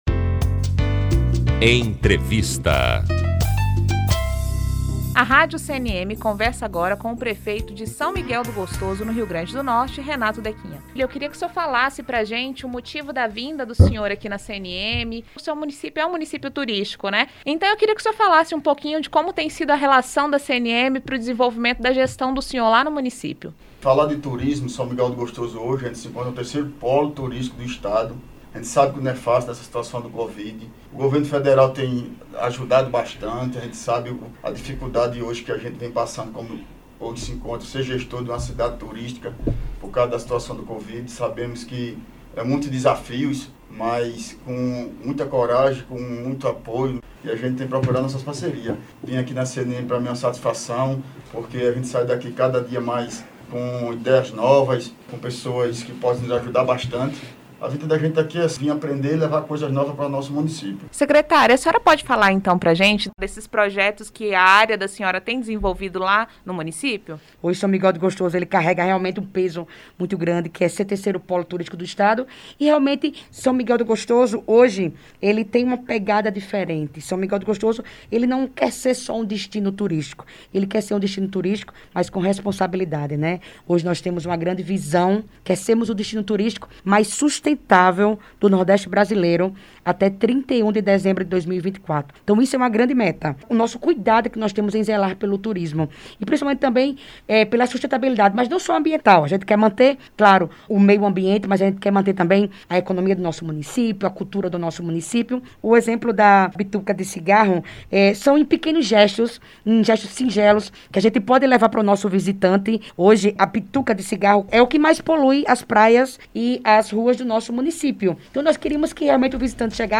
Entrevista - Prefeito José Renato - São Miguel do Gostoso(RN)